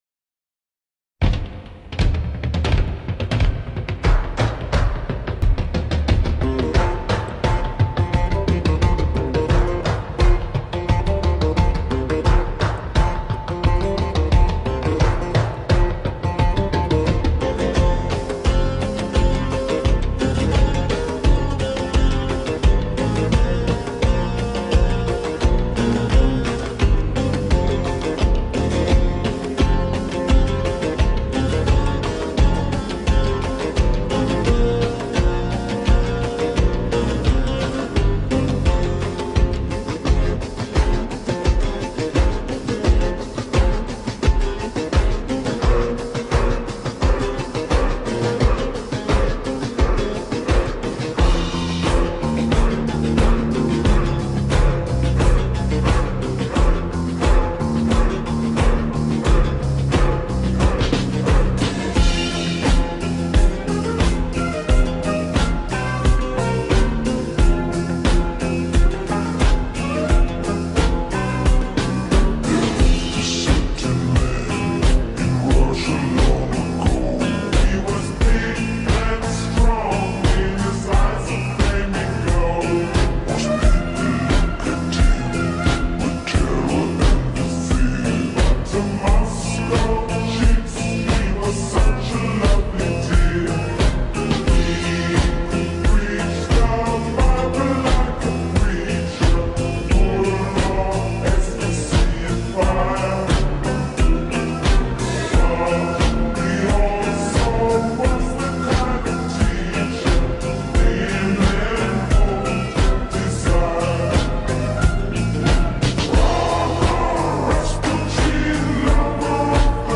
در حالت کند شده و Slowed با کیفیت عالی و لینک دانلود مستقیم